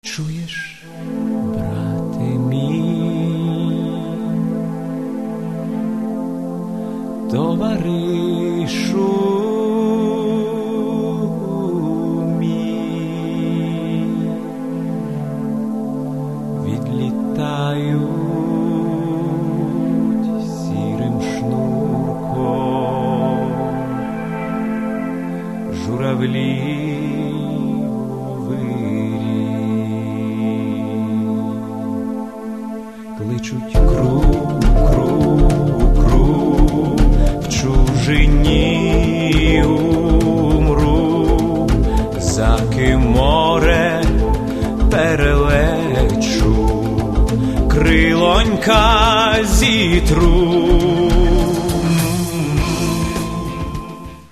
Каталог -> Естрада -> Збірки